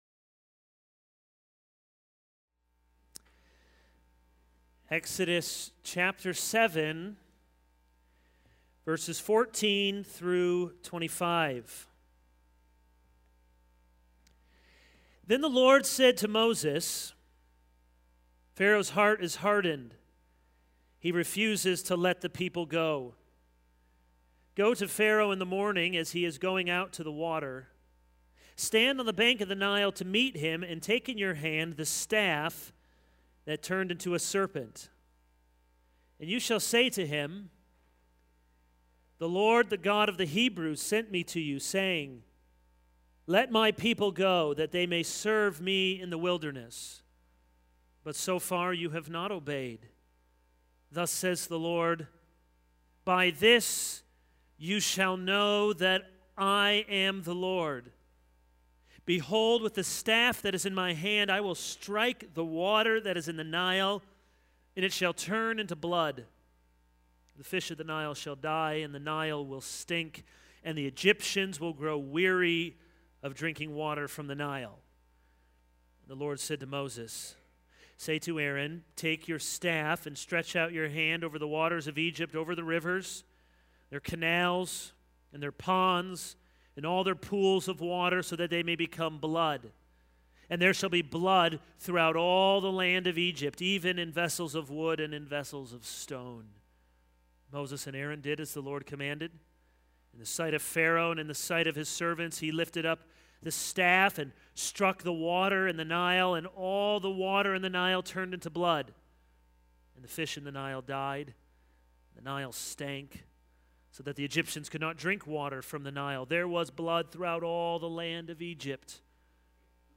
This is a sermon on Exodus 7:14-25.